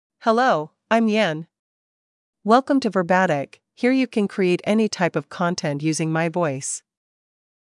FemaleEnglish (Hong Kong SAR)
Voice sample
Female
English (Hong Kong SAR)